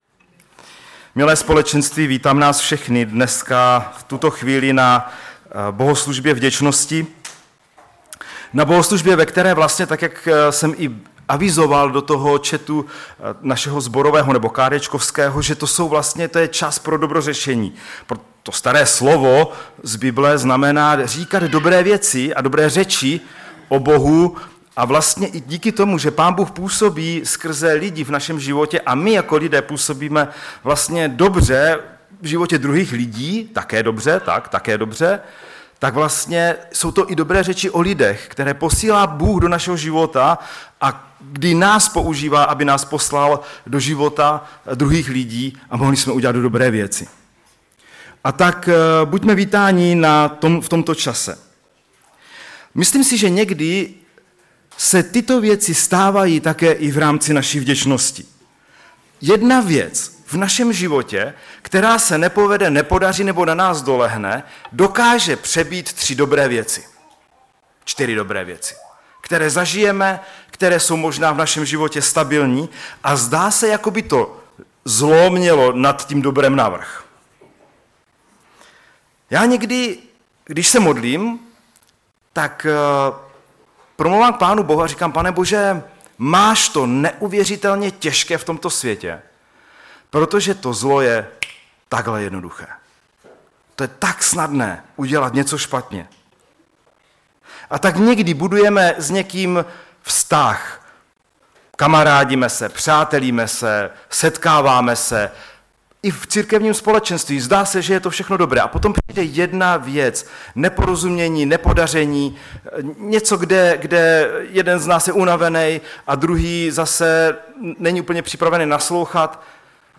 ve sboře Ostrava-Radvanice, při příležitosti Díkuvzdání.
Kázání